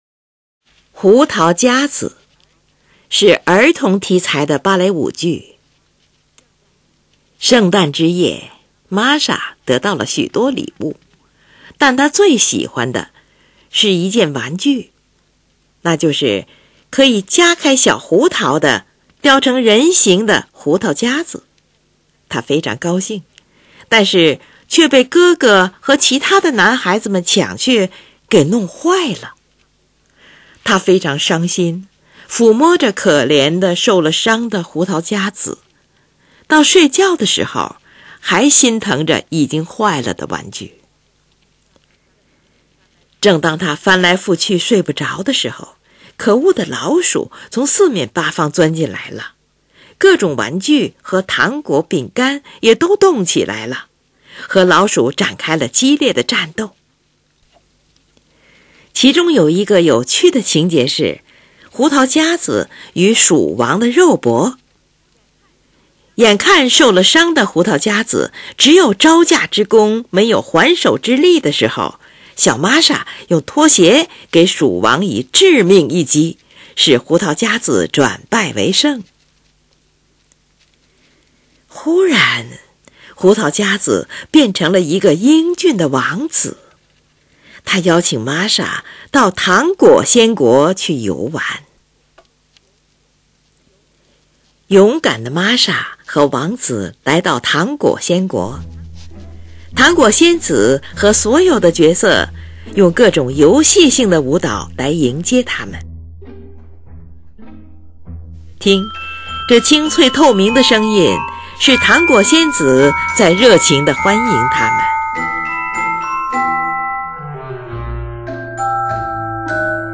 听这清脆透明的声音是糖果仙子在热情地欢迎他们。
钢片琴独奏晶莹清澈的乐句，仿佛听到喷泉上的水珠溅落。